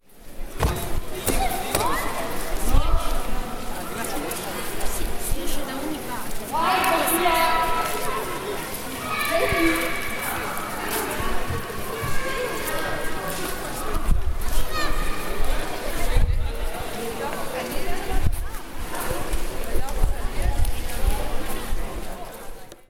Inside the Arena in Verona, empty but for a few tourists on a wet January day, 2014.